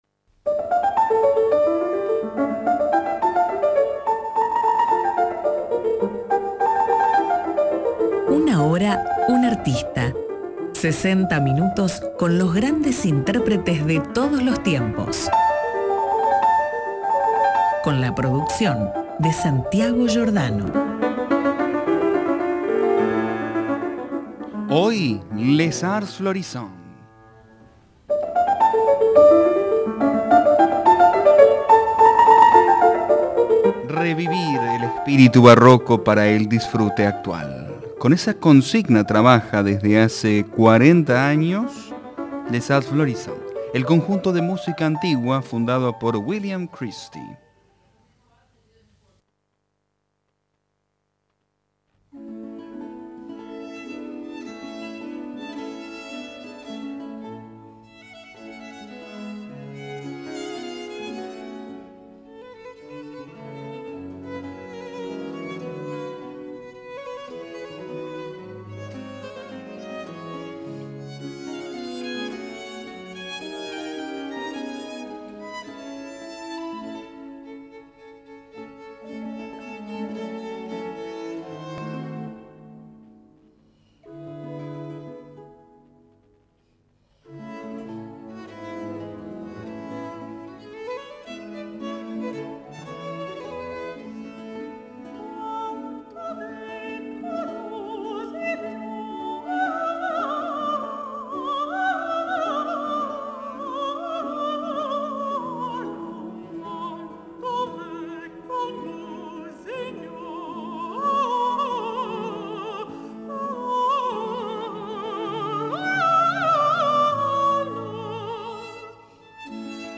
Revivir el espíritu barroco para el disfrute actual, tal es la consigna de este ensamble fundado hace más de 45 años por William Christie. Los invitamos a repasar la trayectoria de uno de los conjuntos de música barroca más reconocidos del mundo, Les Arts Florissants, con obras de Francesco Bartolomeo Conti, Charpentier y Gesualdo.